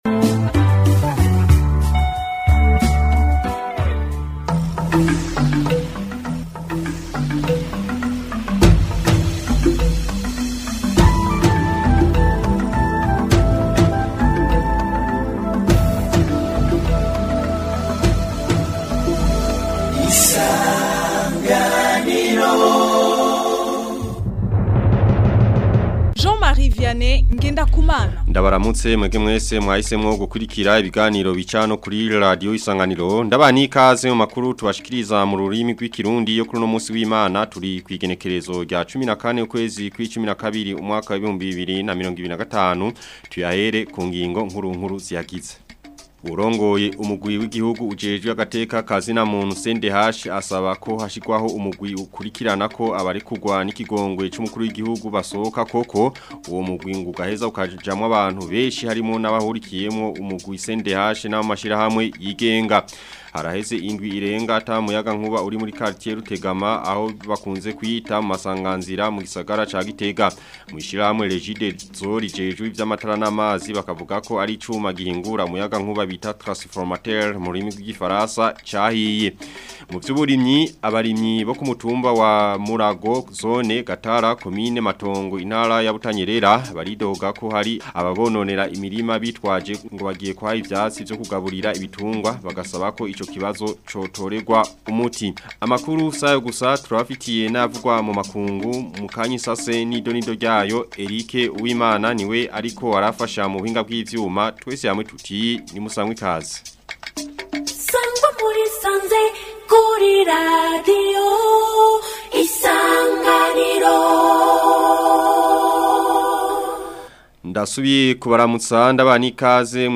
Amakuru yo ku wa 14 Kigarama 2025